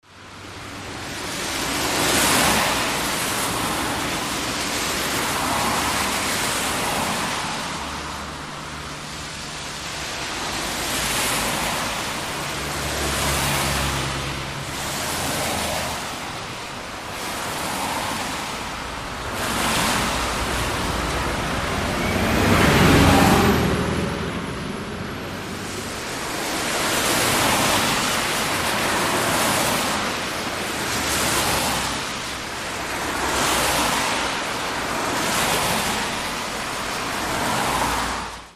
濡れた高速道路を数台の自動車が通過していく走行音です。